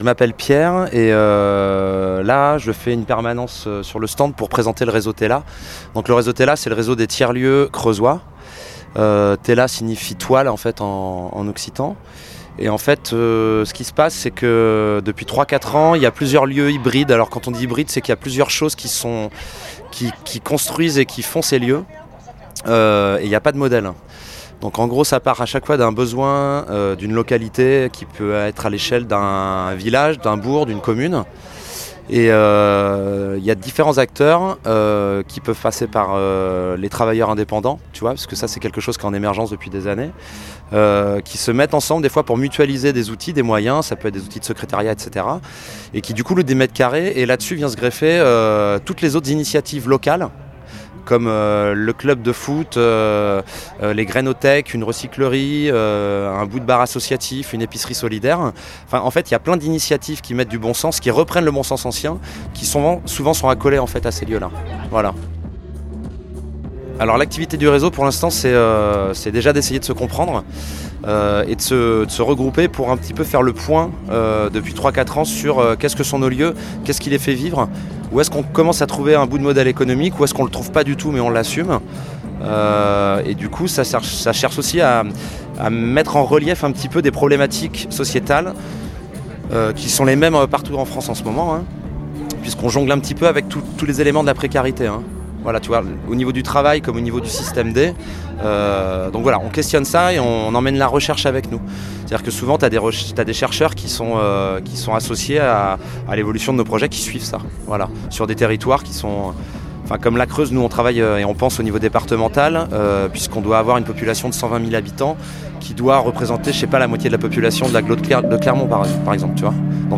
Le Check In Party 2019 avait lieu les 22, 23 et 24 août à l’aérodrome Saint-Laurent de Gueret.